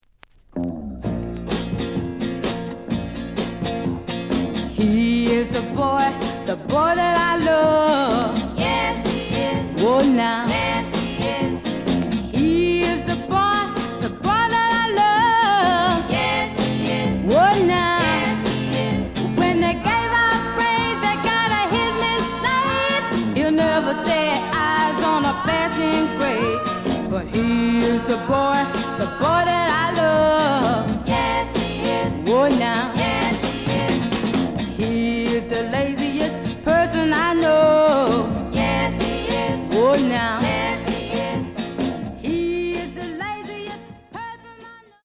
The audio sample is recorded from the actual item.
●Genre: Rhythm And Blues / Rock 'n' Roll
B side plays good.)